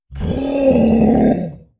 04. Signal Roar.wav